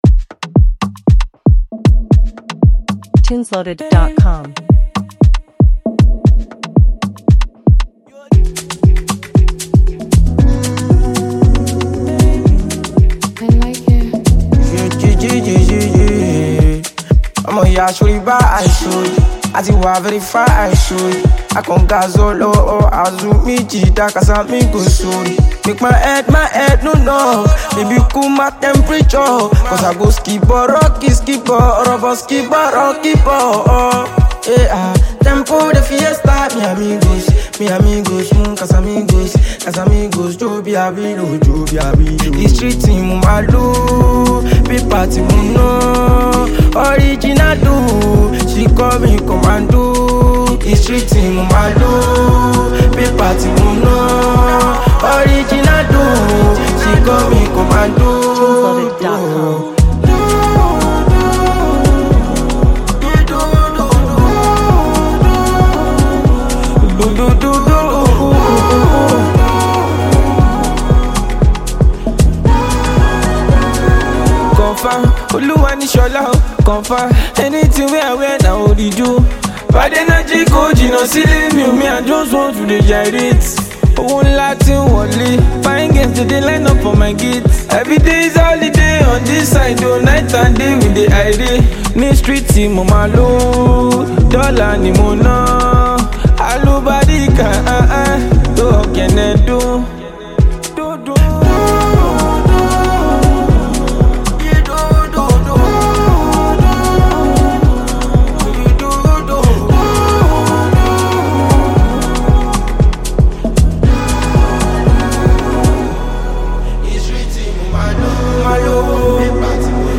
Versatile talented Nigerian Music rapper and producer